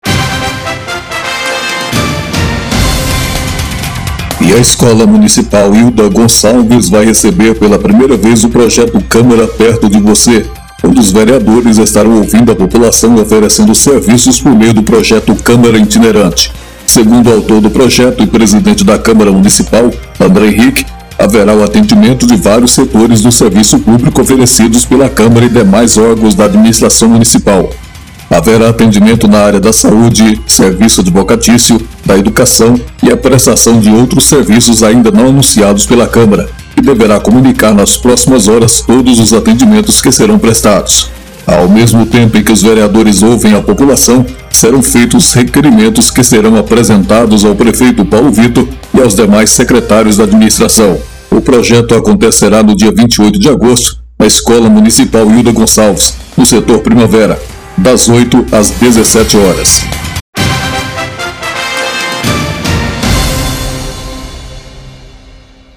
VINHETA-CAMARA.mp3